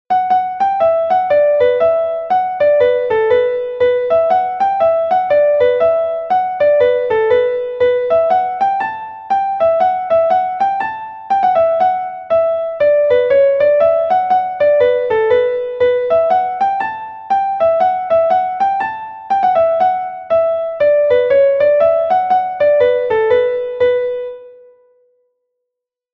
a Gavotte from Brittany